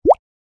На этой странице собраны звуки мыльных пузырей: от мягкого лопанья до переливчатого треска.